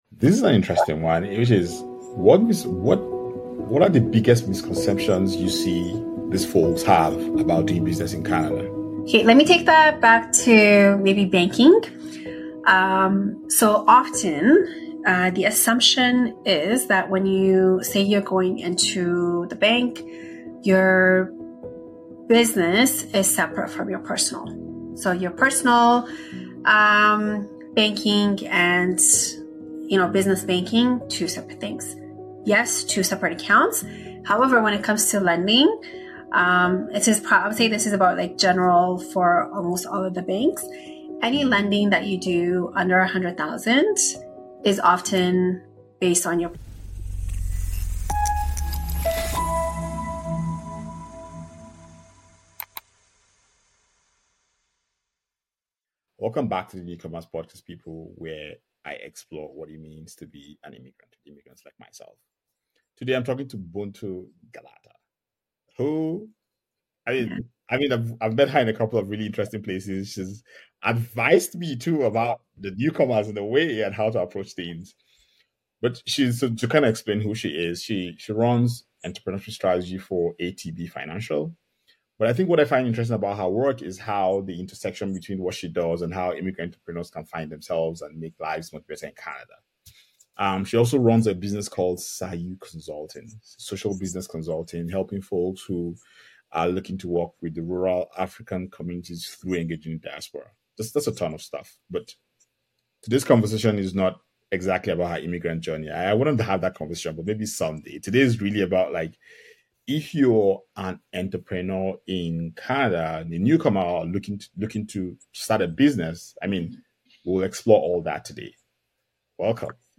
A few things that struck me as I listened through this week's conversation: